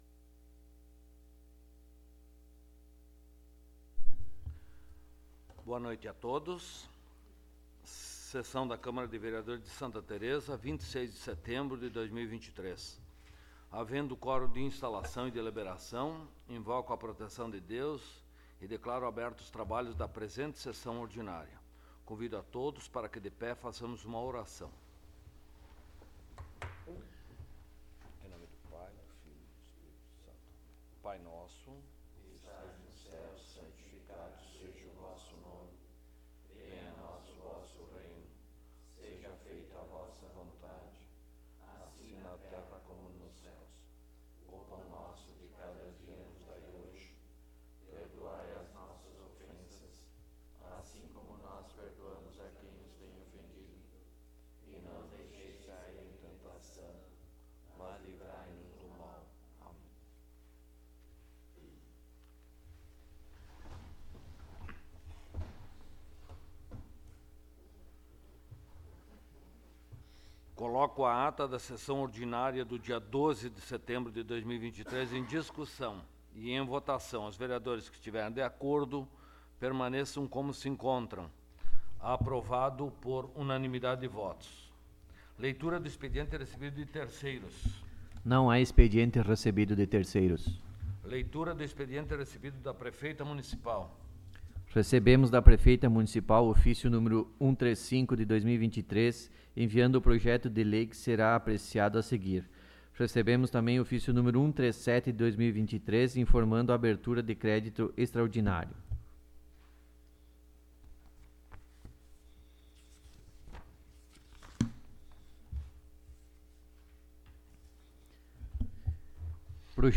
16ª Sessão Ordinária de 2023